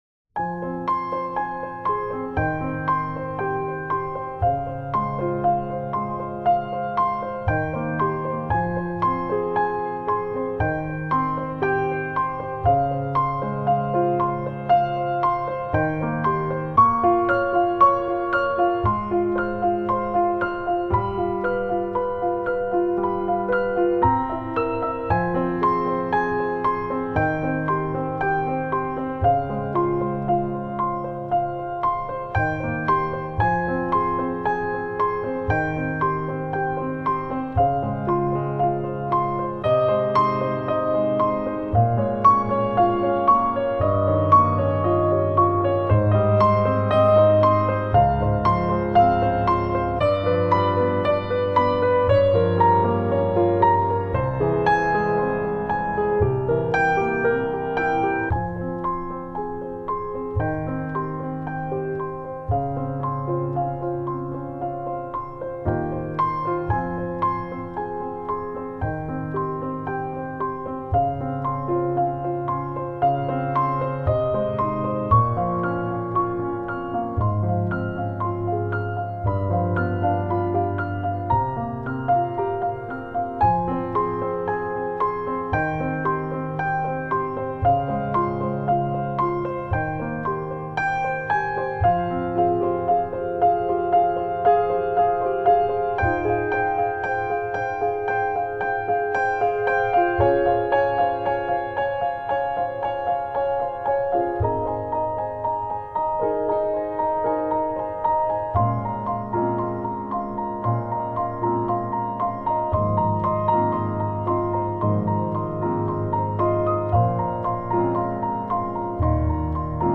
Genre: New Age, Piano